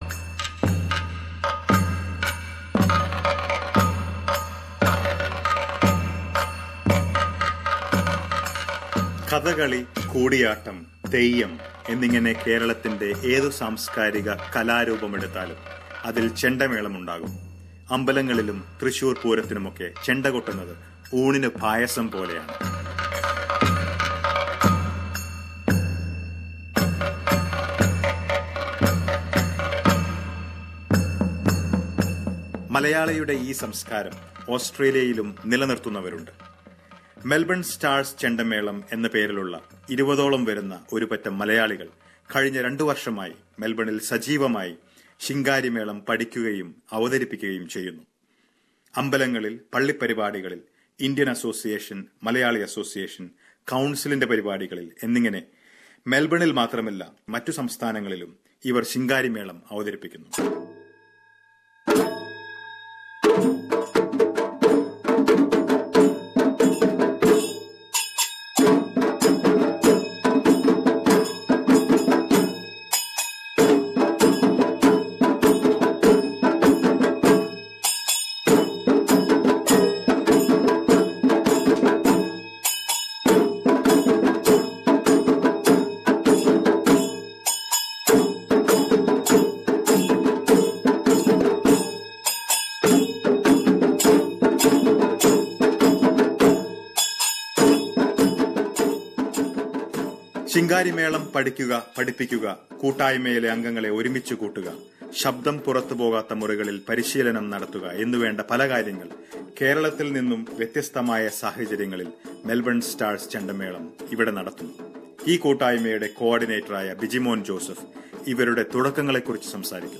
Let us listen to their story and some fantastic drum beats.